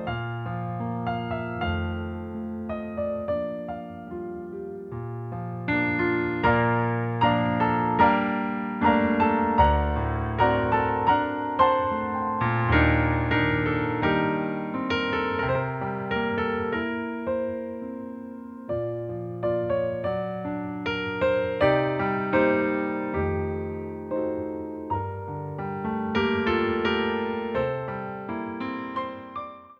Song Titles